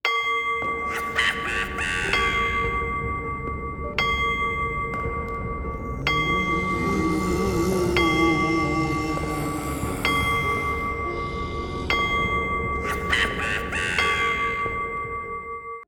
cuckoo-clock-08.wav